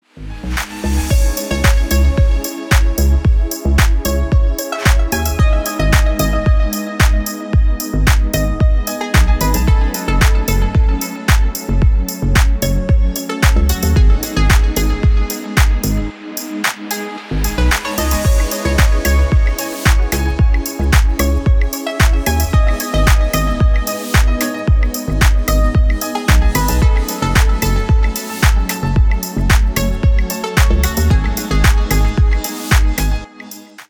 • Качество: 320 kbps, Stereo
Поп Музыка
без слов